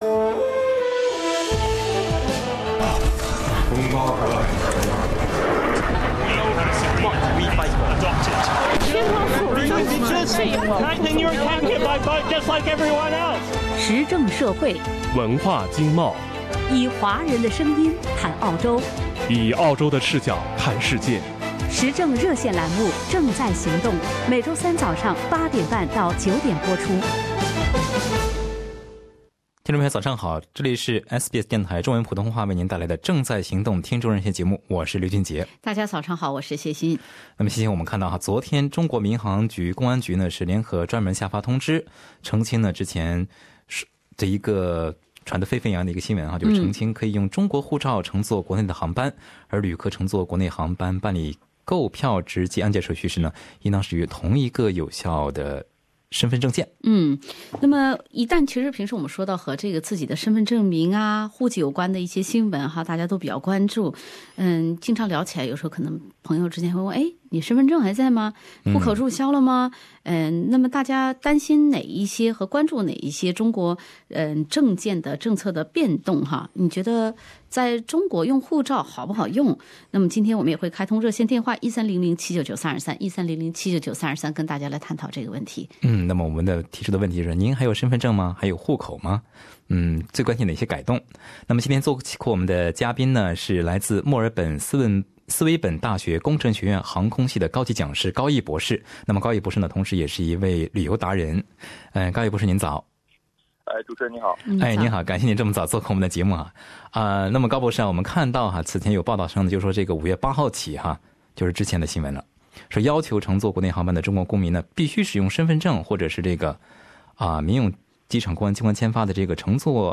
不少听众朋友也分享了他们的经历。